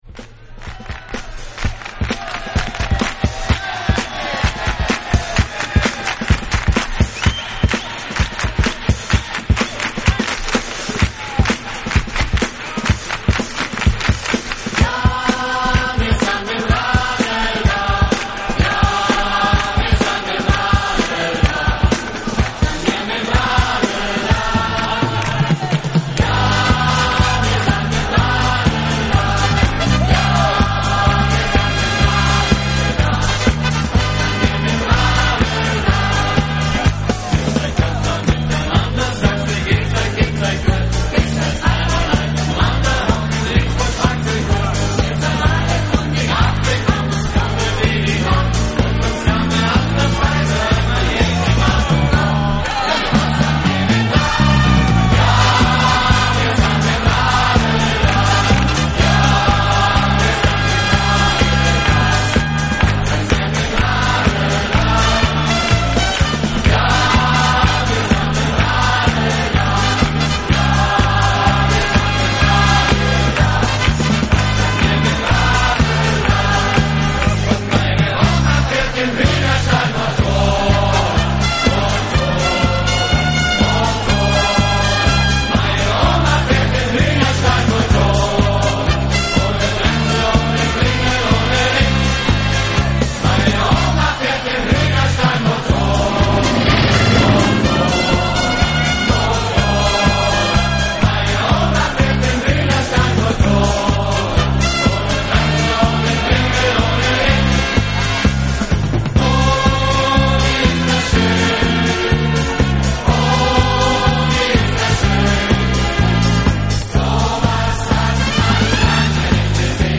【顶级轻音乐】
专门演奏轻音乐乐曲，擅长将古典乐曲改编为现代作品，既不失高雅，又通俗宜听。